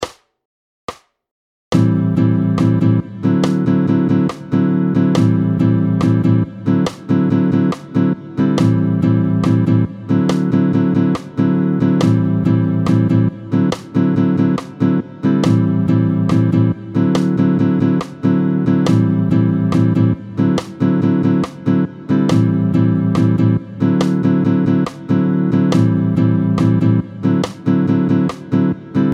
30-01 Lam7 / Ré9. Vite, 2/2 tempo 140